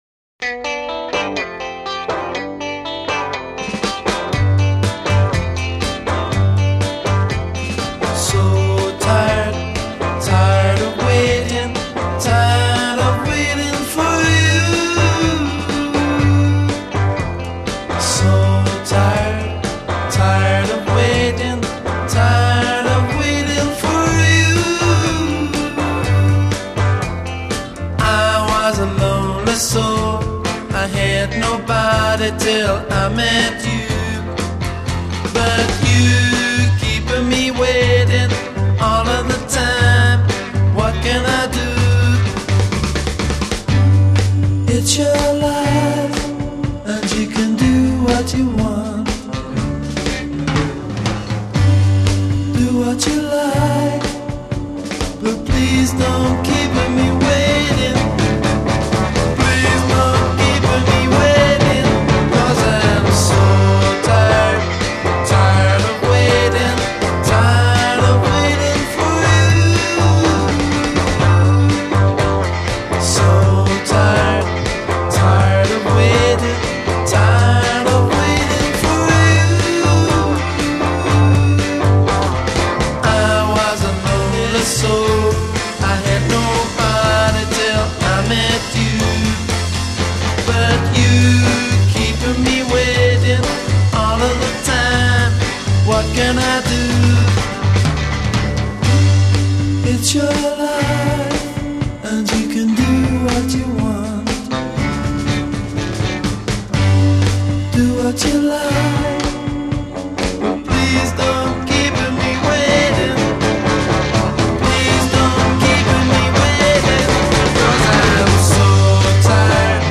A refrain 0: 20 double-tracked solo vocal over riff a
outro : 9 repeat end of hook a'